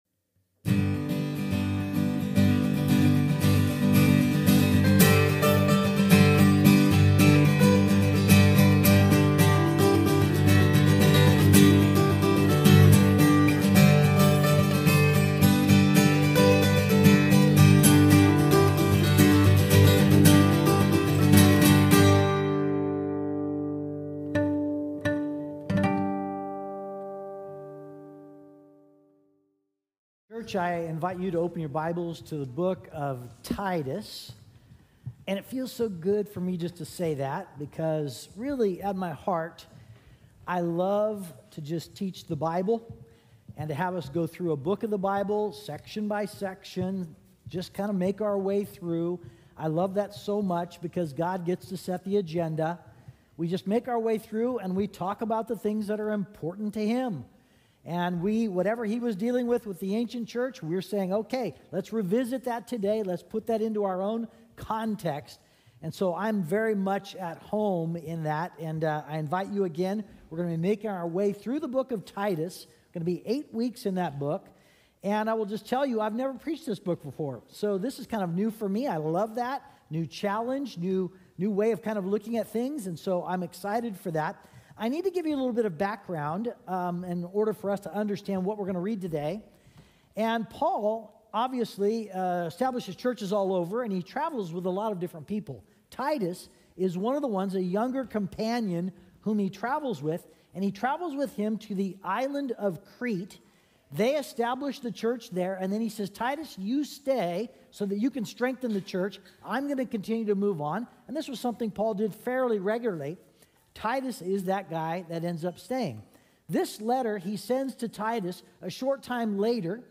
How can we choose joyful worship when we just don't feel like it? Speaker: